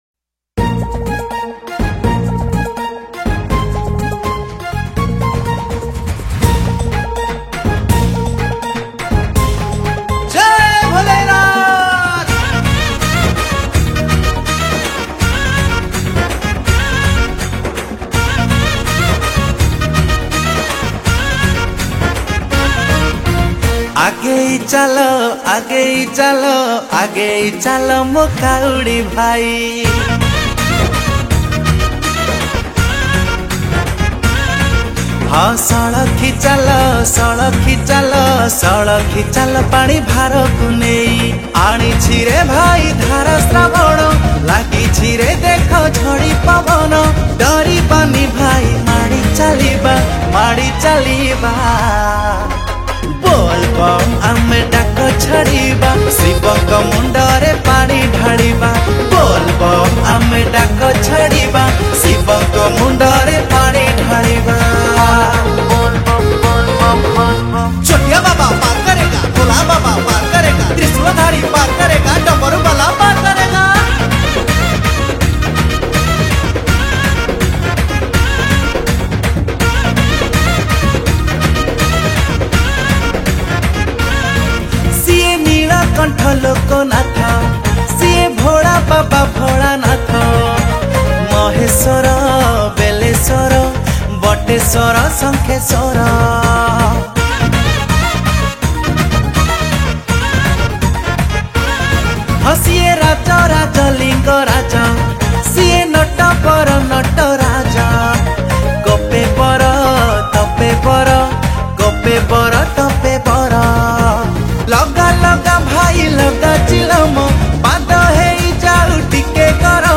Bolbum Special Song Songs Download